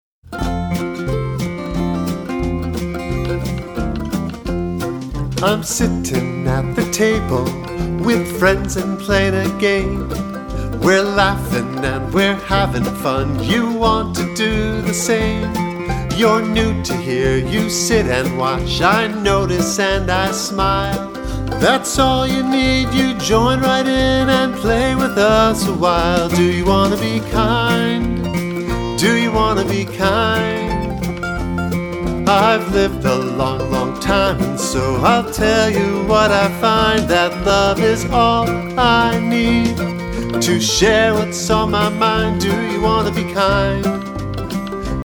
With a youthful, warm and energetic style